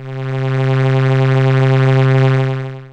LO ARP STRS.wav